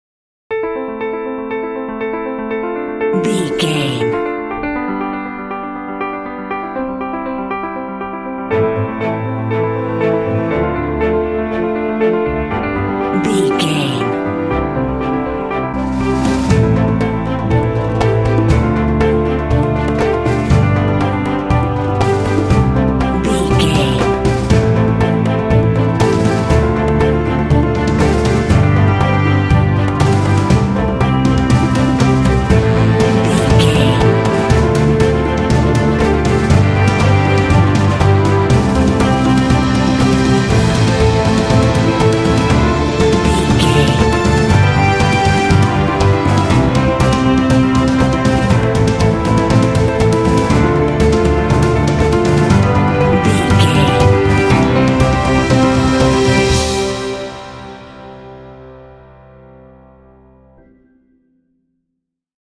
Epic / Action
Fast paced
Ionian/Major
Fast
epic
dreamy
intense
drums
cinematic